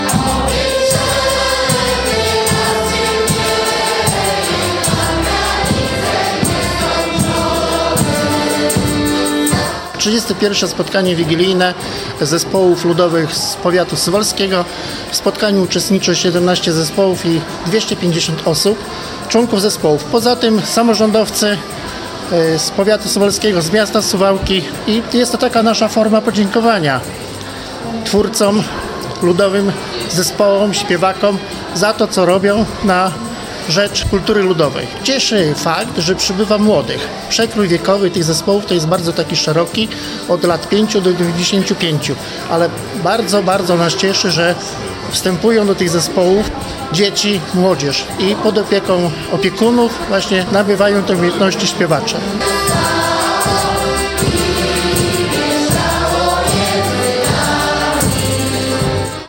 Suwałki 31. Wigilia Chłopska odbyła się w sobotę (14.12) w Suwałkach.